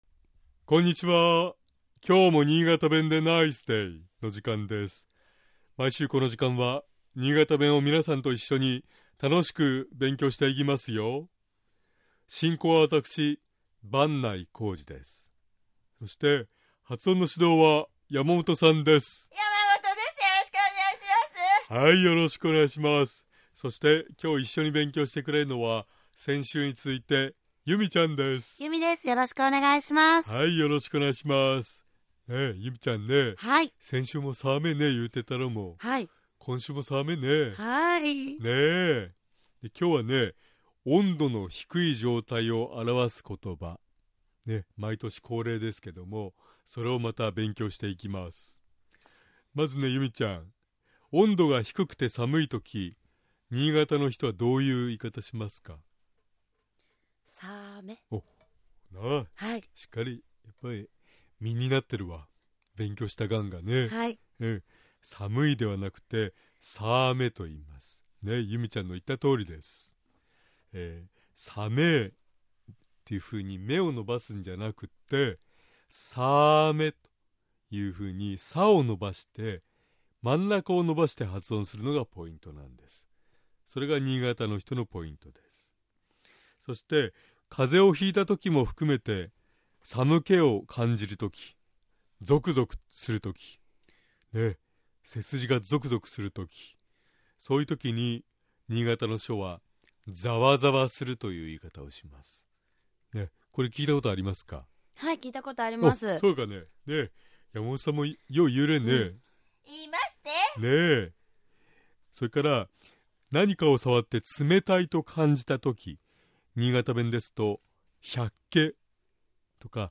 「め」を伸ばして「さめぇ」と言うのではなく、 「さ」を伸ばして「さぁめ」と発音するのがポイントです。